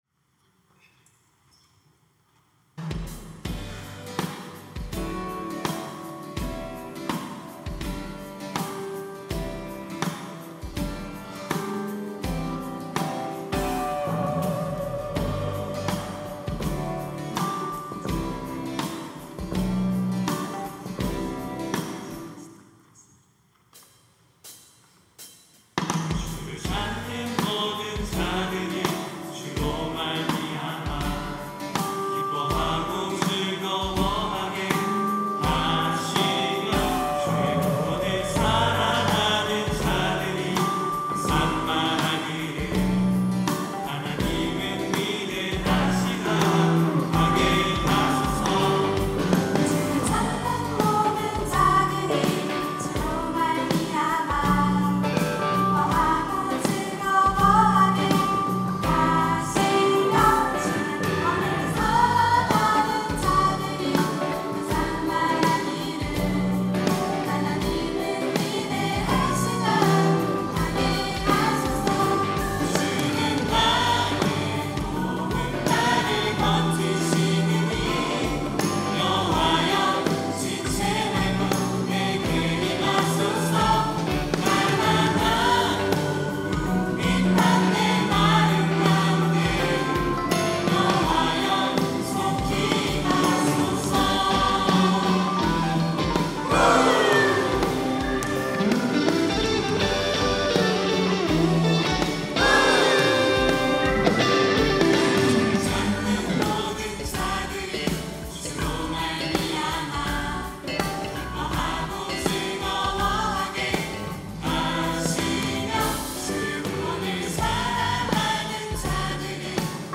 특송과 특주 - 주를 찾는 모든 자들이
청년부 새가족팀